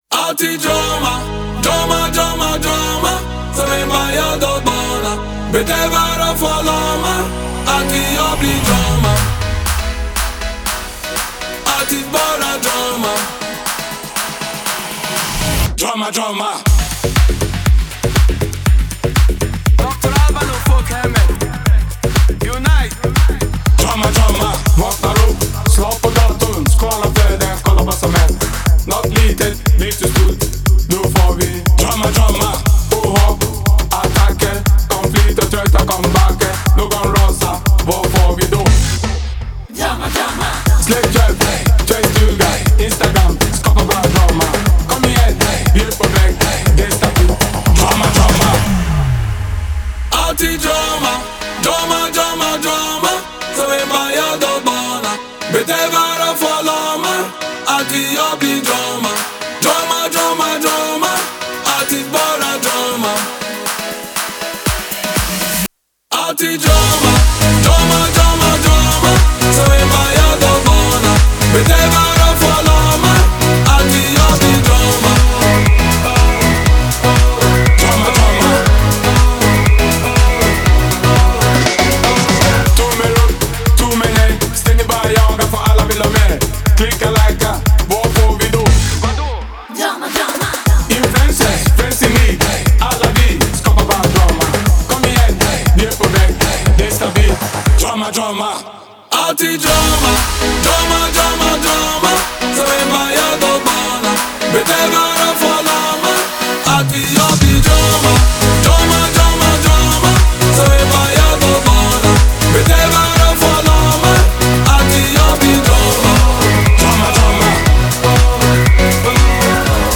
это энергичная и зажигательная композиция в жанре Eurodance